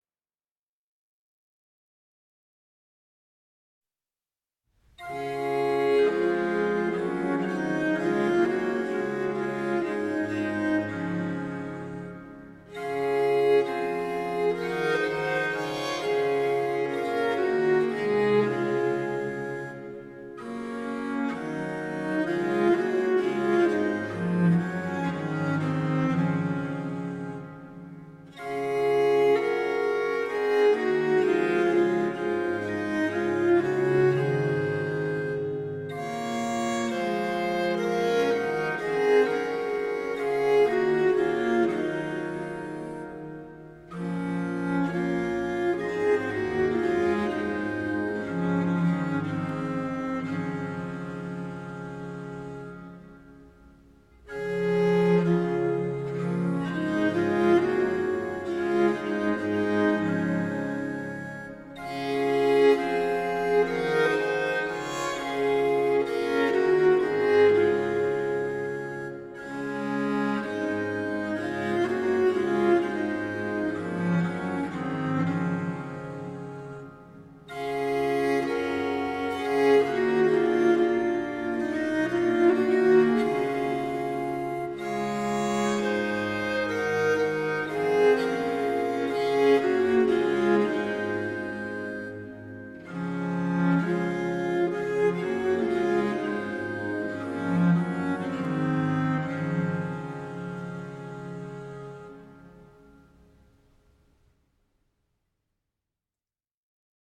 HYMN: Psalm 47; para.
psalm-47-genevan-psalter-setting-by-anonymous-and-goudimel.mp3